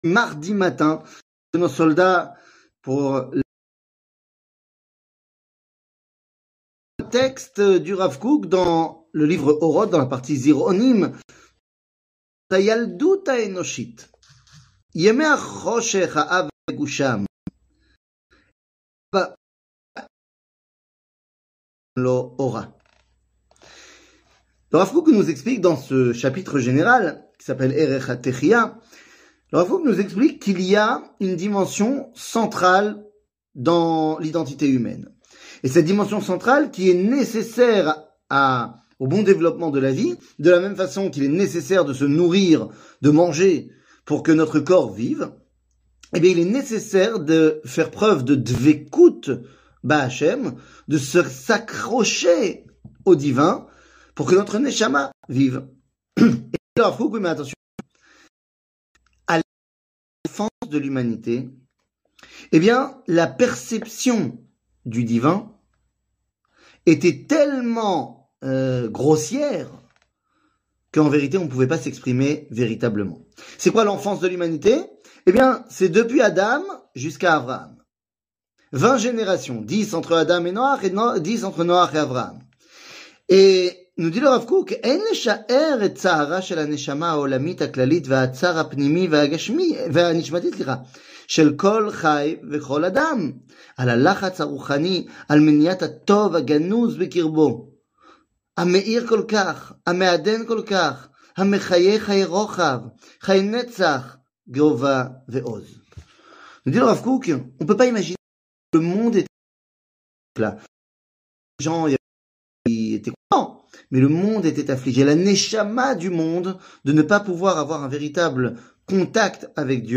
Rav Kook, Zironim, L'enfance de l'humanite 00:06:07 Rav Kook, Zironim, L'enfance de l'humanite שיעור מ 26 דצמבר 2023 06MIN הורדה בקובץ אודיו MP3 (5.6 Mo) הורדה בקובץ וידאו MP4 (9.39 Mo) TAGS : שיעורים קצרים